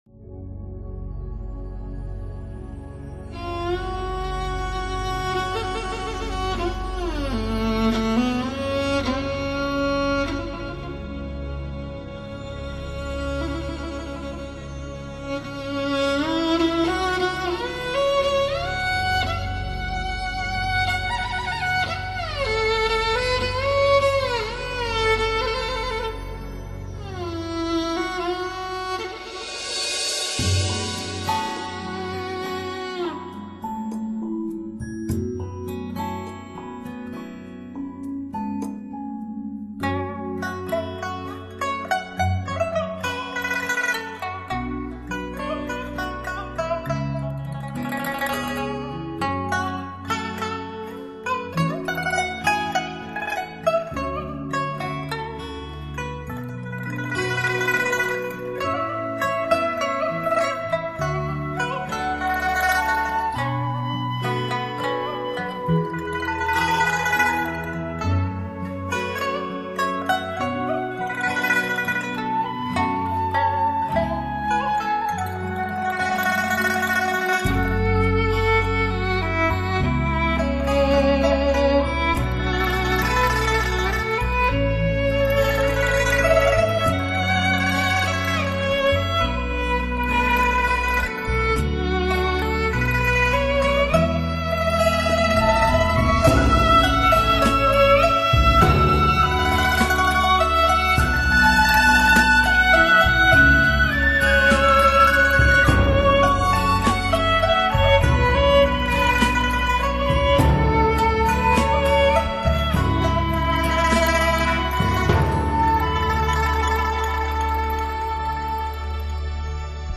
迷幻电子乐精品演奏
让您在大草原中享受轻松舒缓的同时